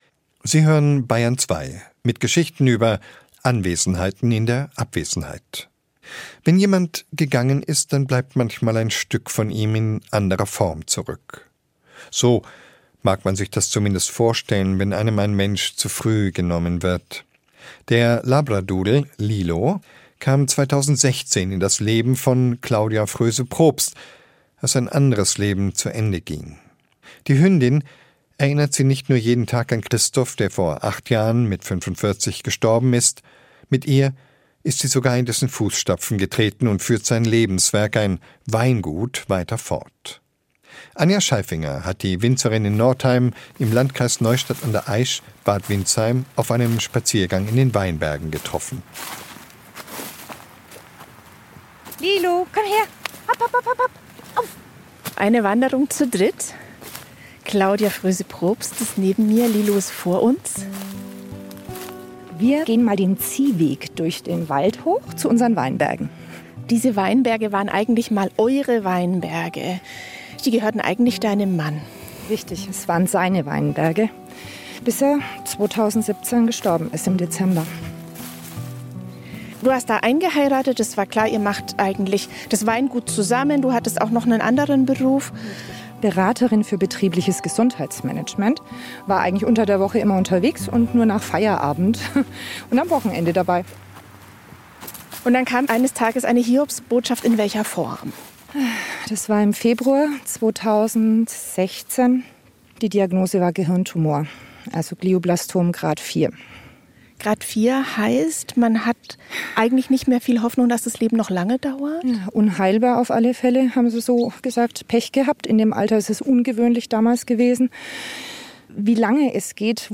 HÖRT VON UNS BAYERN 2-INTERVIEWS In „Zeit für Bayern“ im Feiertags-Feuilleton “Abwesend da“ gesendet am 1.